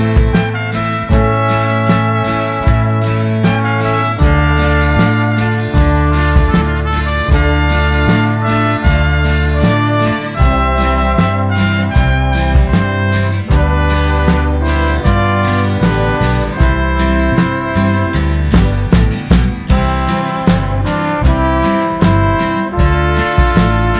For Solo Male Pop (1970s) 4:06 Buy £1.50